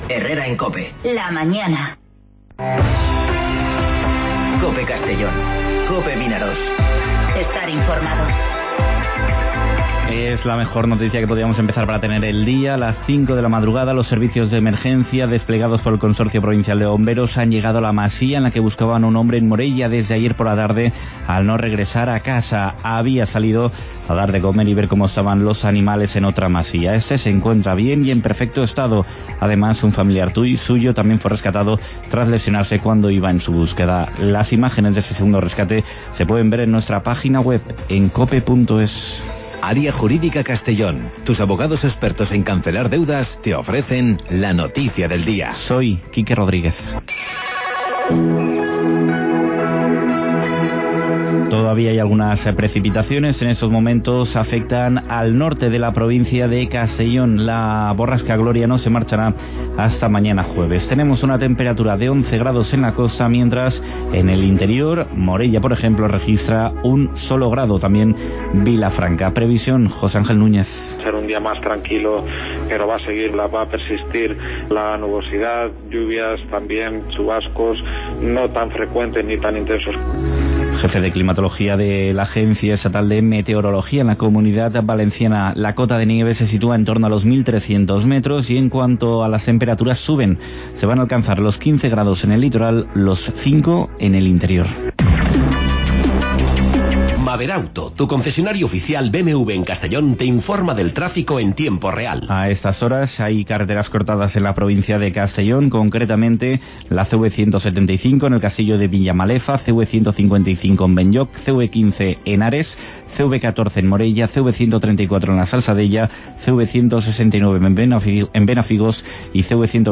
Informativo Herrera en COPE Castellón (22/01/2020)